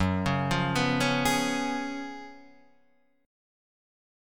F#M7sus2sus4 chord